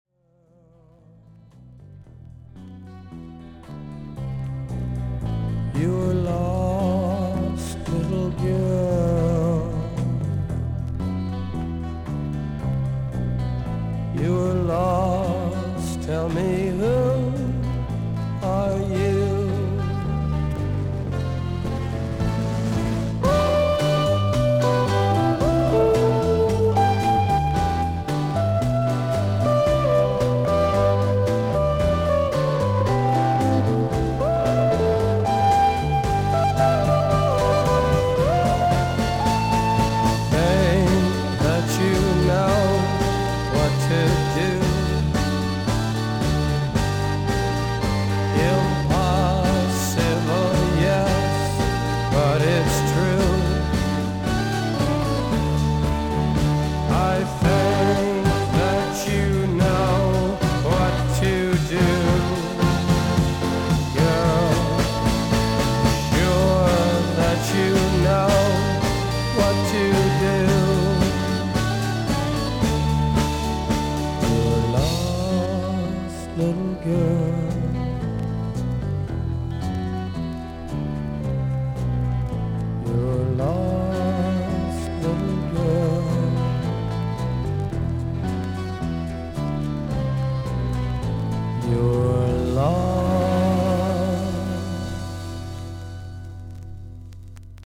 A2中盤から終わりにかけて斜めに1.8cmのキズがありますが、それほど音に出ません。
少々サーフィス・ノイズあり。クリアな音です。